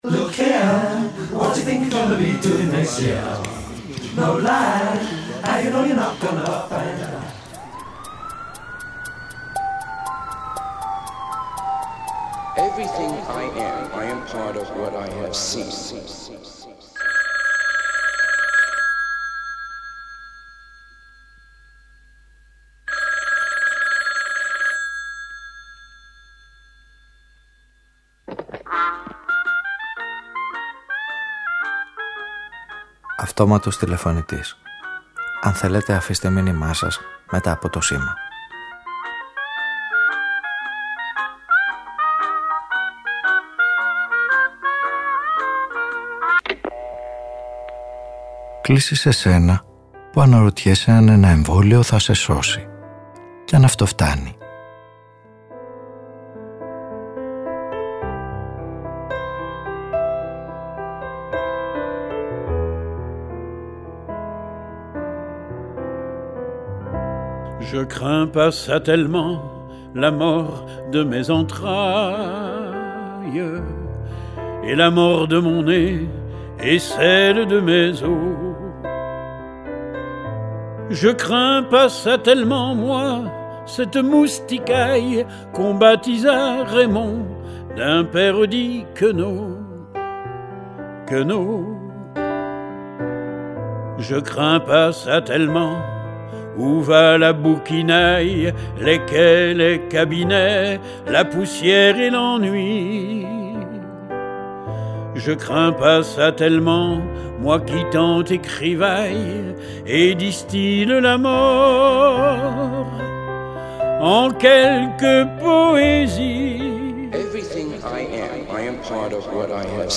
Ο ήρωας της σημερινής ραδιοφωνικής ταινίας αναρωτιέται αν ένα εμβόλιο θα τον σώσει και επιστρατεύει ως συνεργούς όσους έμαθαν πως κάθε συμφορά είναι στην πραγματικότητα τόσο βαριά, όσο βαριά τη νιώθεις. Τι θα σώσει τον πλανήτη από τις καταστροφές που -συνήθως- ο ίδιος ο άνθρωπος δρομολογεί;